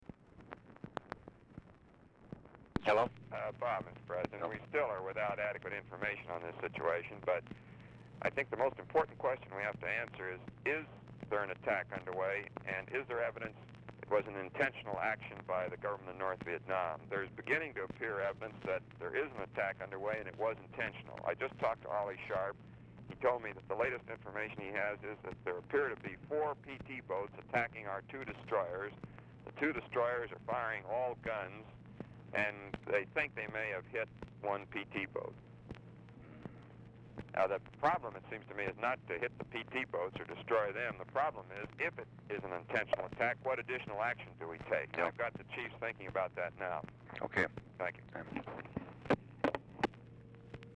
Telephone conversation # 5591, sound recording, LBJ and ROBERT MCNAMARA, 9/18/1964, 10:18AM | Discover LBJ
Format Dictation belt
Location Of Speaker 1 Mansion, White House, Washington, DC